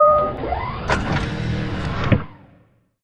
New Door Sounds